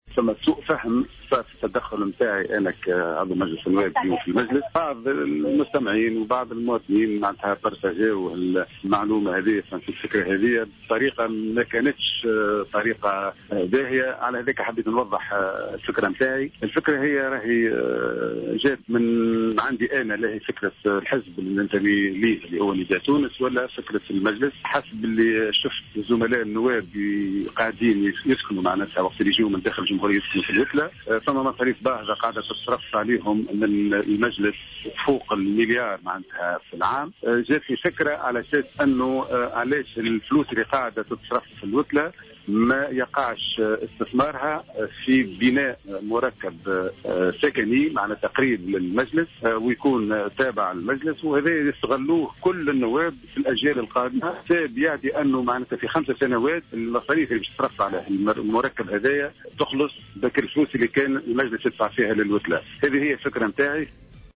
أكد النائب إبراهيم ناصف عن حزب نداء تونس في تصريح ل"جوهرة أف أم" أنه دعا إلى بناء مركب سكني قرب مجلس الشعب يستغله النواب الحاليون والقادمون نظرا لارتفاع كلفة مصاريف الإقامة في النزل والتي بلغت مليون دينار،وفق تعبيره.